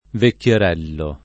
vekkLer$llo] (oggi meno com. vecchiarello [vekkLar$llo]) s. m. — es.: La stanca vecchiarella pellegrina [la St#jka vekkLar$lla pellegr&na] (Petrarca); Siede con le vicine Su la scala a filar la vecchierella [SL$de kon le vi©&ne Su lla Sk#la a ffil#r la vekkLer$lla] (Leopardi) — sim. il cogn. Vecchiarelli